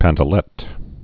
(păntə-lĕt)